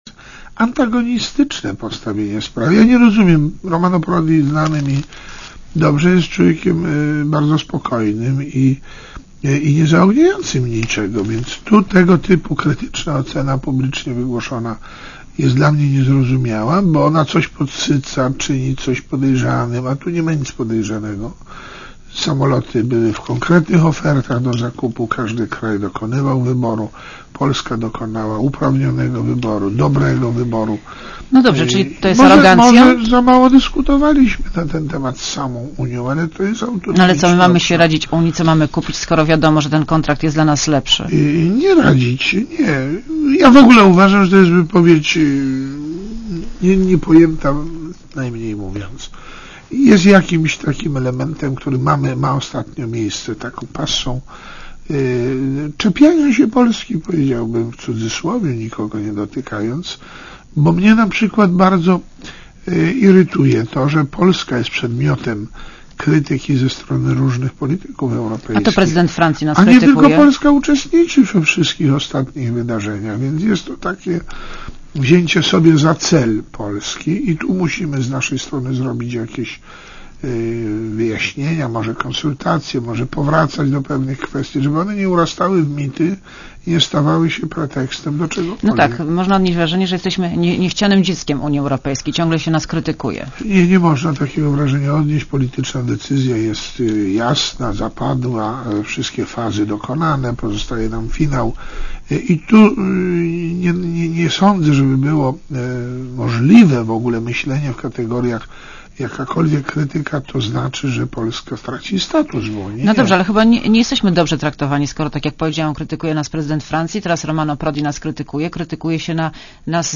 © (RadioZet) Posłuchaj wywiadu (2,4 MB) Co pan powie na słowa Romano Prodiego, przewodniczącego Komisji Europejskiej , który krytykuje nas za podpisanie kontraktu z Amerykanami tuż po podpisaniu traktatu akcesyjnego.